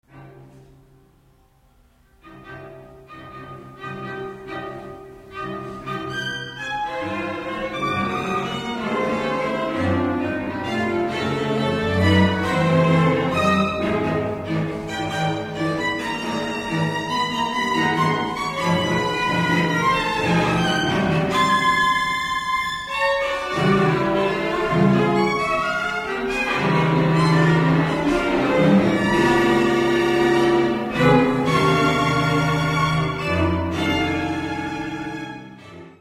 streichquintett
andante